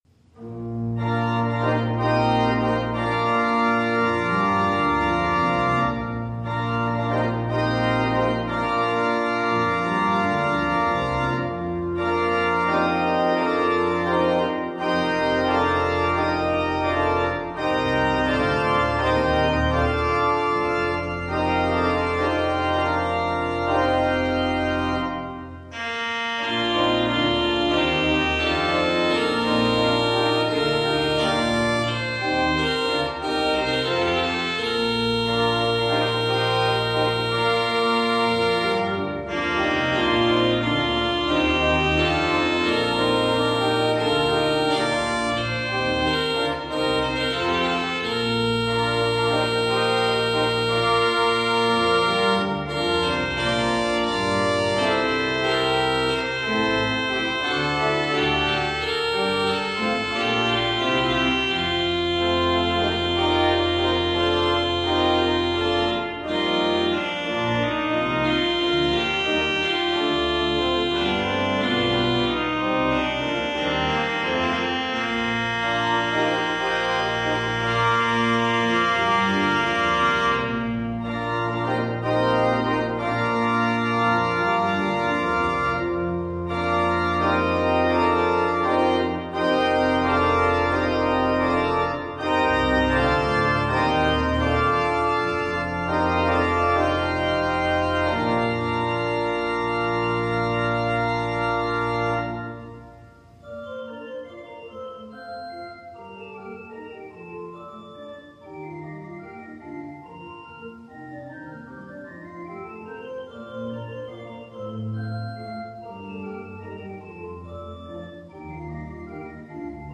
We begin Palm Sunday with a special procession featuring our children and families, recorded outside our church building. On this final week of Lent, we savor stories, re-telling of Jesus’ entry into Jerusalem.